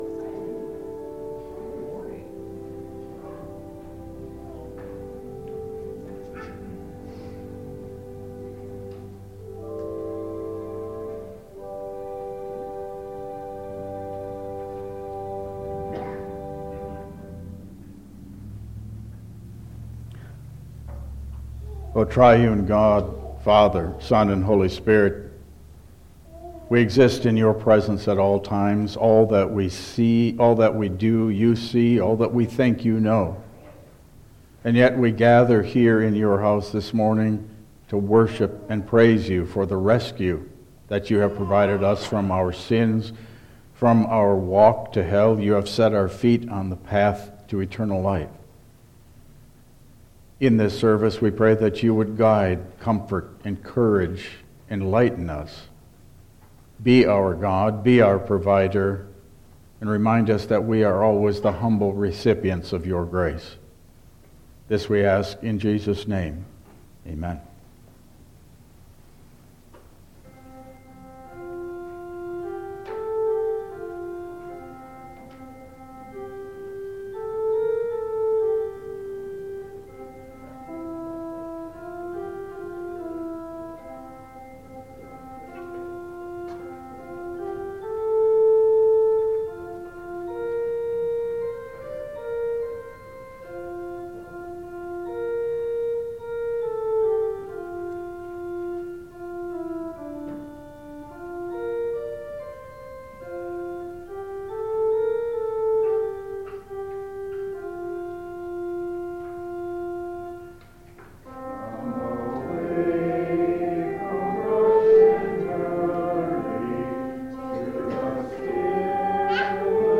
Download Files Printed Sermon and Bulletin
Passage: 2 Thessalonians 3:1-6, 10-16 Service Type: Regular Service